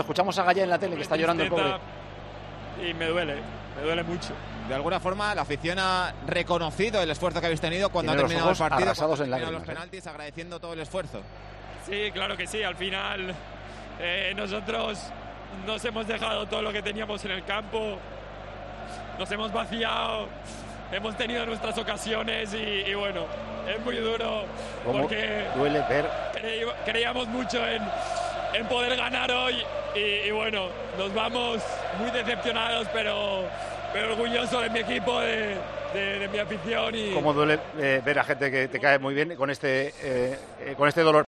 Las lágrimas de Gayá tras perder la final de la Copa del Rey: "Nosotros lo hemos dejado todo en el campo"
El capitán del Valencia se pasó por los micrófonos de Mediaset tras caer en la final de la Copa del Rey: "Nos vamos decepcionados, pero orgullosos de mi equipo"